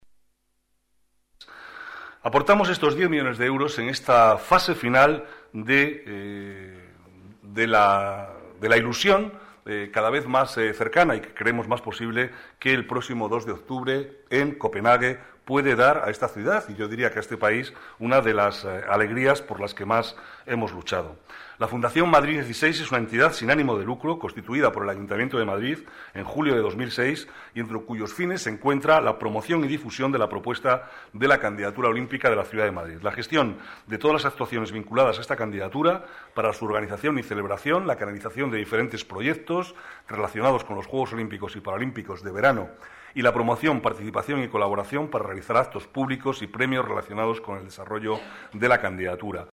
Nueva ventana:Declaraciones vicealcalde, Manuel Cobo: subvención Madrid 16